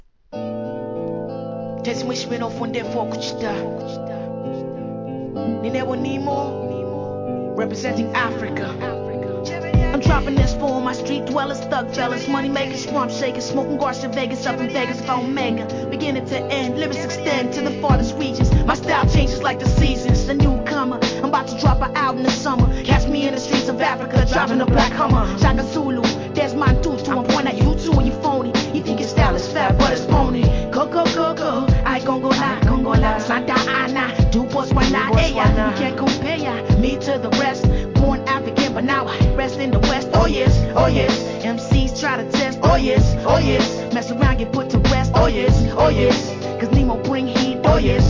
HIP HOP/R&B
マイナー哀愁メロ〜HIP HOP!!